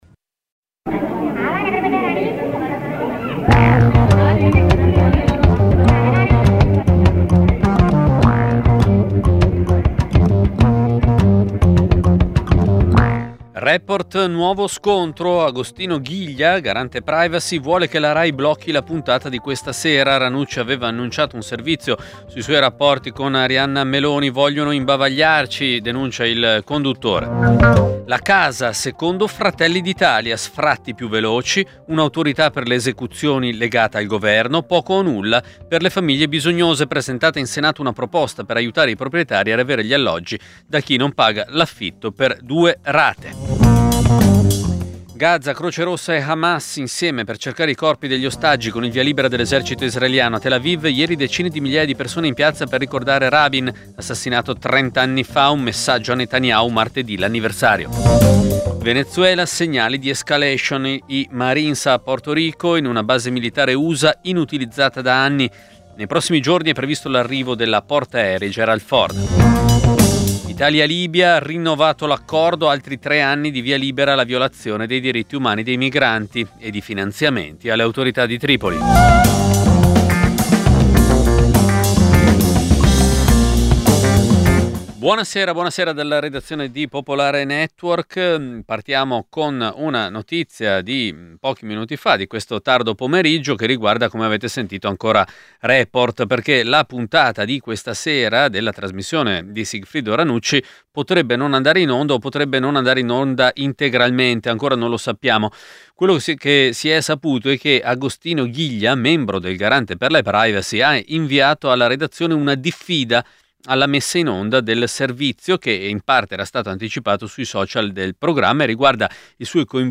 Radio Popolare, Giornale radio 2 novembre (servizio dal minuto 4:30)